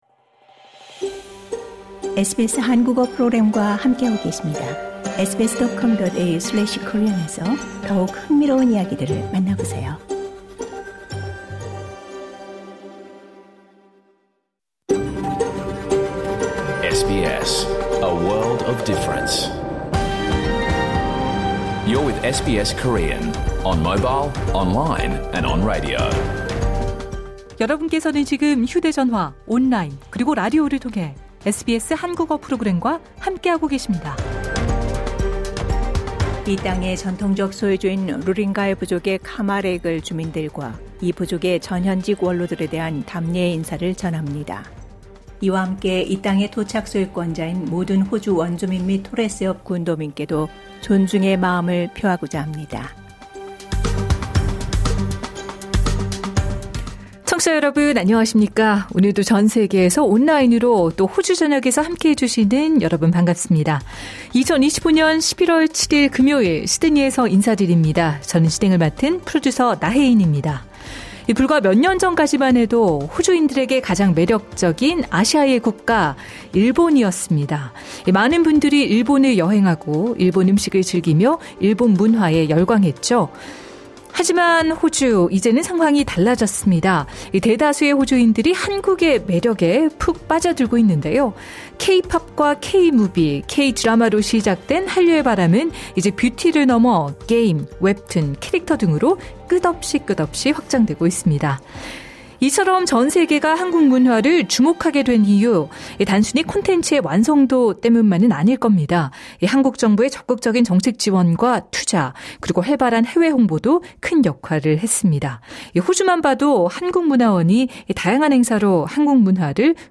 2025년 11월 7일 금요일에 방송된 SBS 한국어 프로그램 전체를 들으실 수 있습니다.